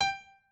pianoadrib1_55.ogg